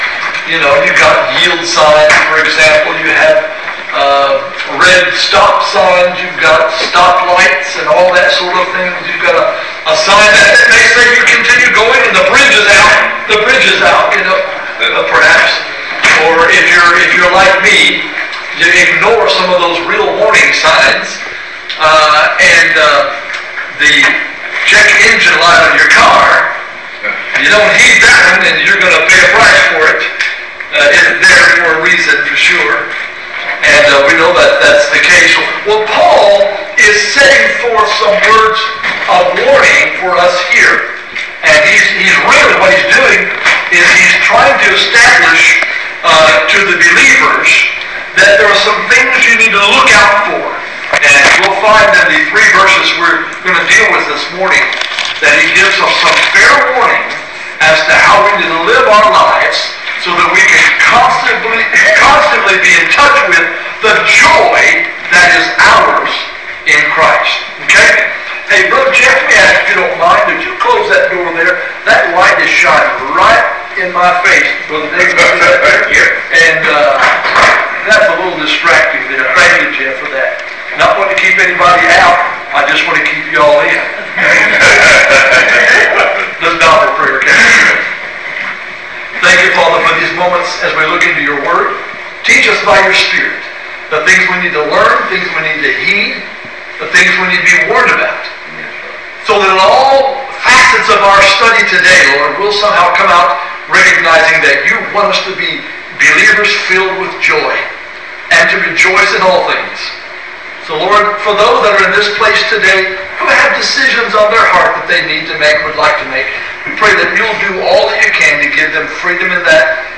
Sermons | Jackson Ridge Baptist Church